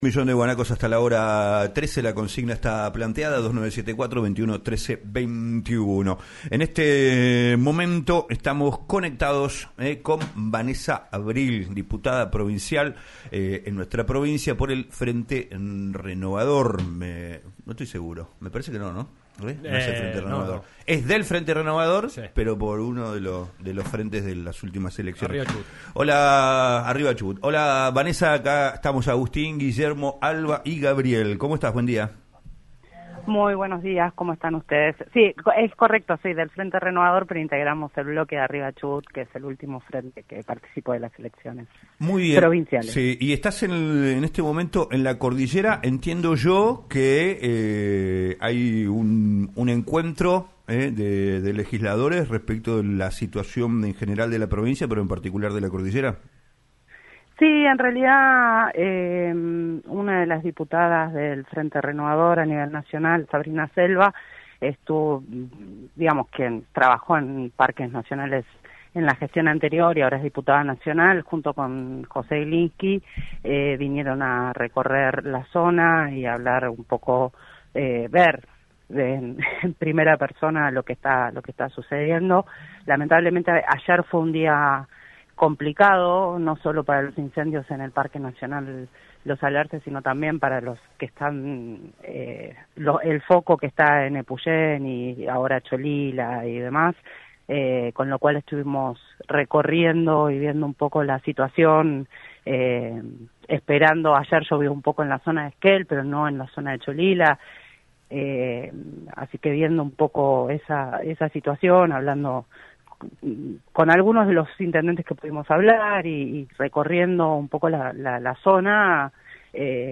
Vanesa Abril, diputada provincial por el bloque Arriba Chubut, habló en "Un Millón de Guanacos" por LaCienPuntoUno sobre la situación de los incendios en la cordillera tras el avance del fuego en zonas residenciales de la Comarca Andina.